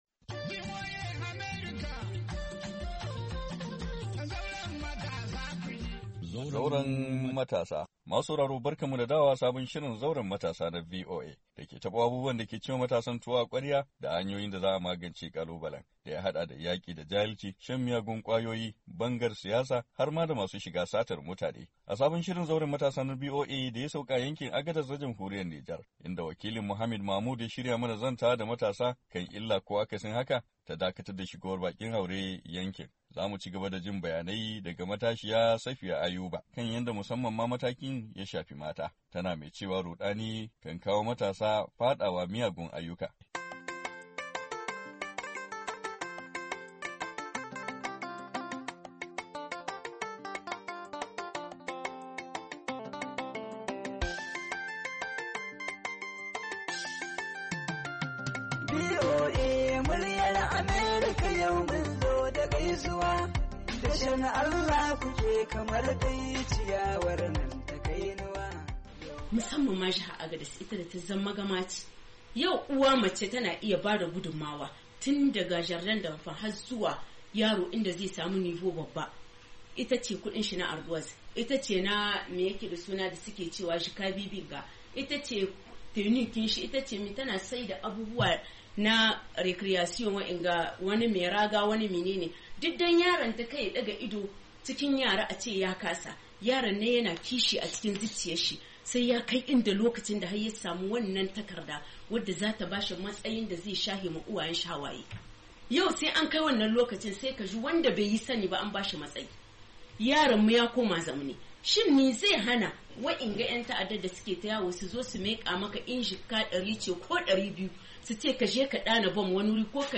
Kashi na biyu na kuma karshe na shirin da a ka dauka a yankin Agadas na jamhuriyar Nijar inda hana shigar bakin haure yankin ya taba hanyoyin samun kudin shiga ga matasa. Tun 2016 gwamnatin Nijar ta sanya hannu da turai na hana bakin shiga Agadas don gudun hijira zuwa turai ta hanyar da ta sabawa doka.